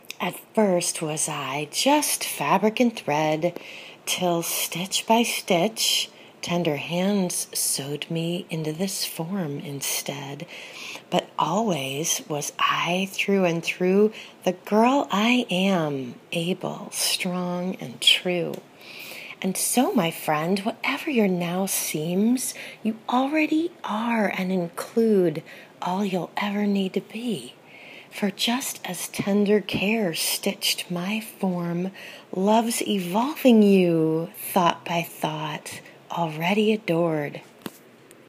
A poem…